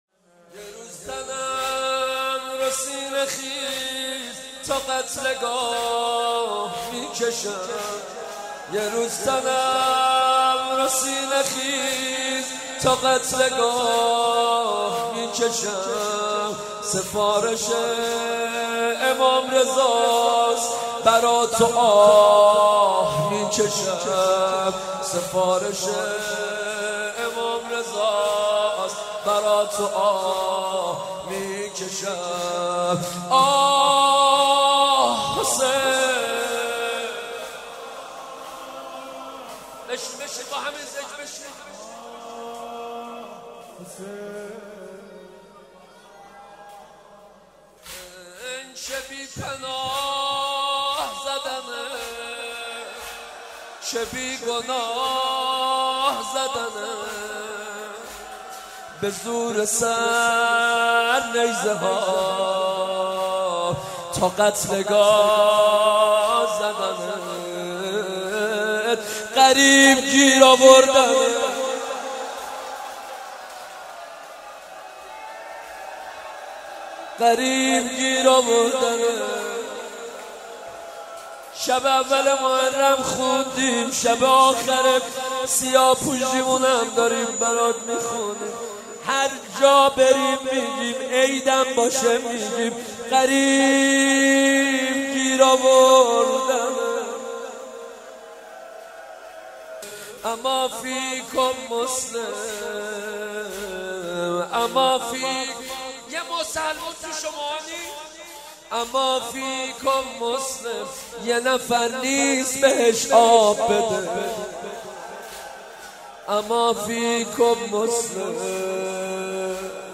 دانلود جدیدترین و گلچین بهترین مداحی های محرم